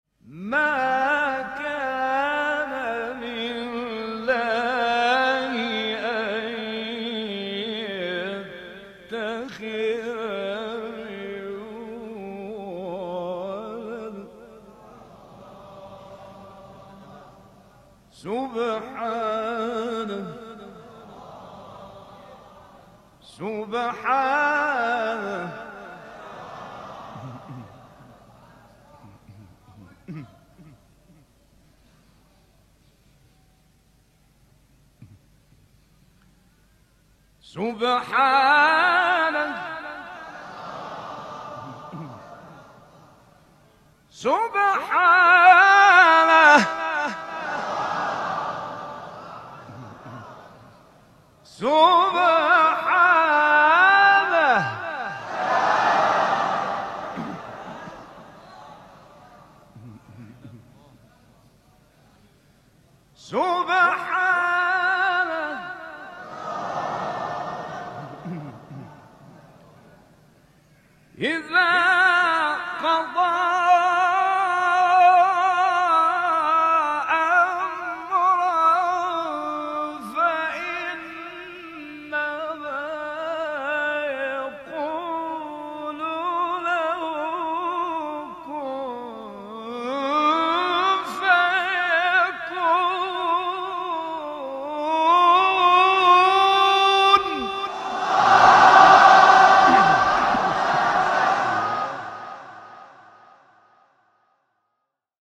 سوره : مریم آیه: 38 استاد : راغب مصطفی غلوش مقام : مرکب خوانی (بیات * صبا) قبلی بعدی